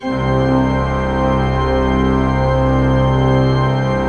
Index of /90_sSampleCDs/Propeller Island - Cathedral Organ/Partition F/MAN.V.WERK M